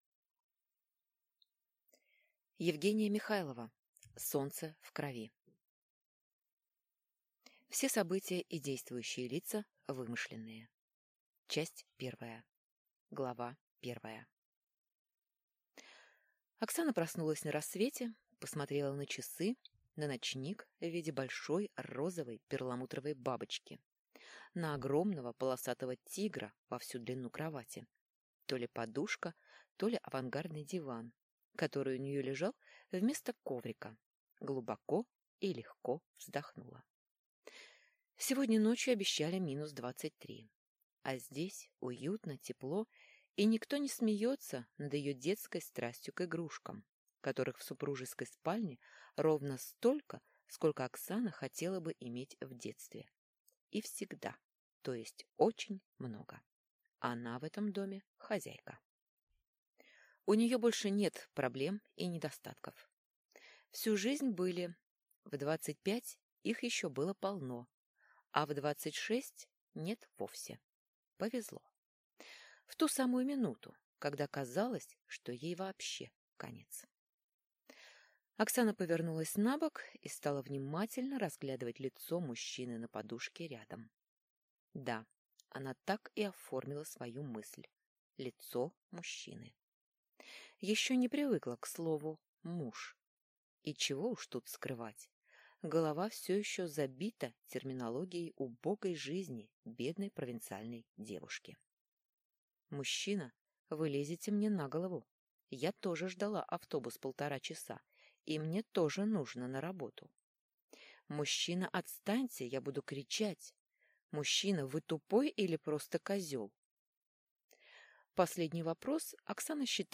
Аудиокнига Солнце в крови | Библиотека аудиокниг